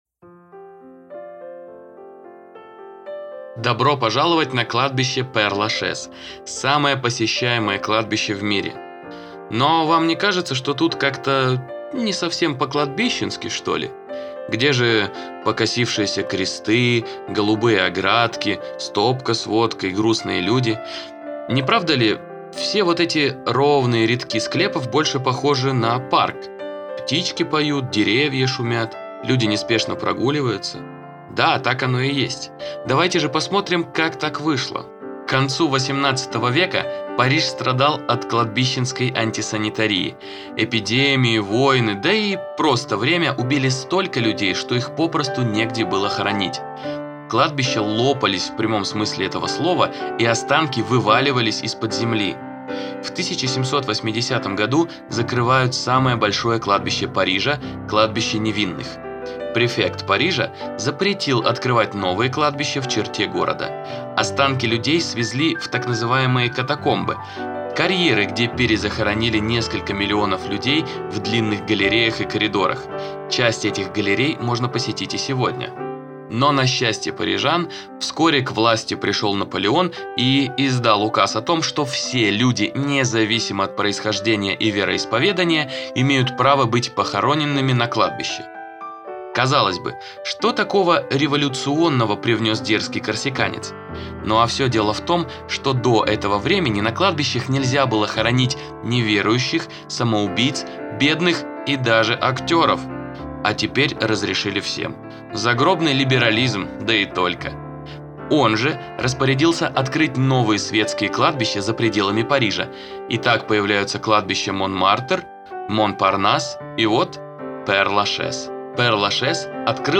Легенды кладбища Пер-Лашез в аудиогиде от TouringBee